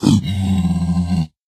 Minecraft Version Minecraft Version latest Latest Release | Latest Snapshot latest / assets / minecraft / sounds / mob / zombified_piglin / zpigangry3.ogg Compare With Compare With Latest Release | Latest Snapshot
zpigangry3.ogg